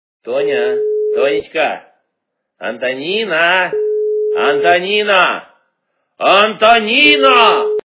При прослушивании Именной звонок для Антонины - Тоня, Тонечка, Антонина, Антонина, Антонина качество понижено и присутствуют гудки.